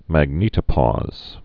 (măg-nētə-pôz)